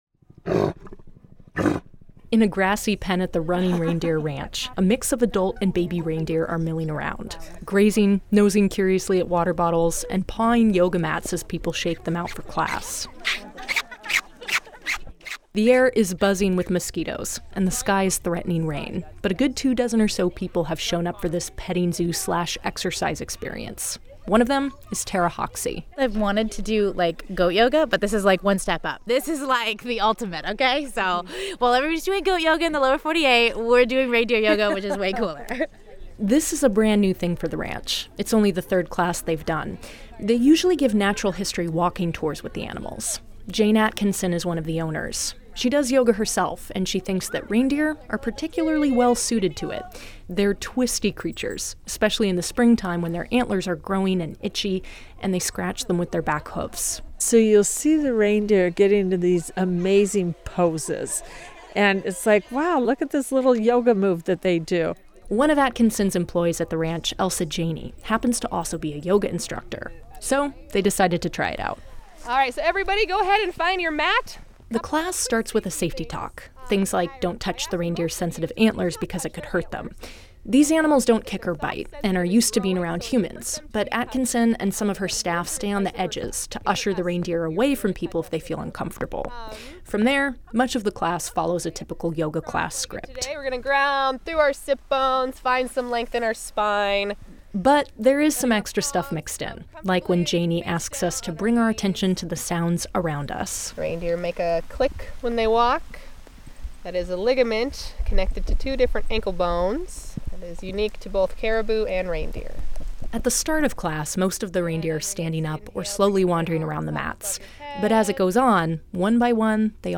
The air is buzzing with mosquitoes, and the sky is threatening rain, but a good two dozen or so people have shown up for this petting zoo/exercise experience.
We spend about an hour going through our yoga poses and all the while, the reindeer are just living their peaceful lives around us: chewing on weeds, making funny grunting noises from time to time, and at least once, availing themselves of the grass toilet.
The whole thing is pretty surreal and there’s a lot of giggling.